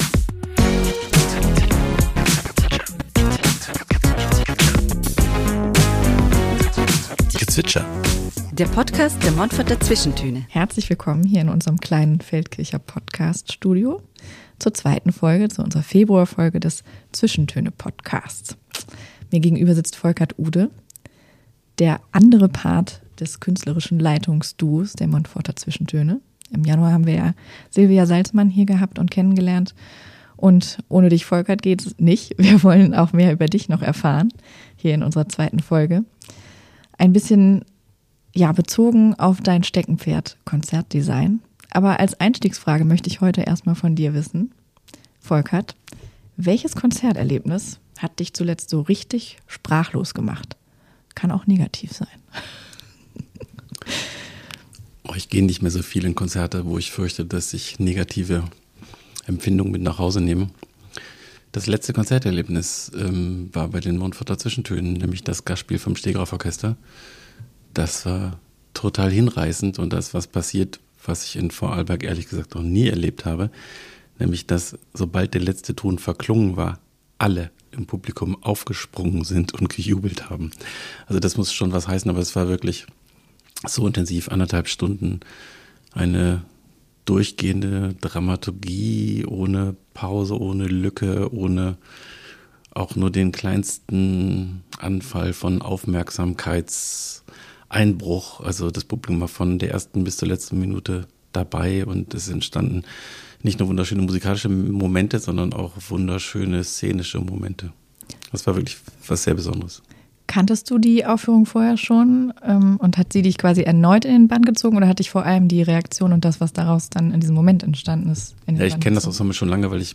Ein Gespräch über neue Perspektiven, die Kraft der Kunst und die Frage, wie Kultur Räume für differenziertes Erleben schaffen kann.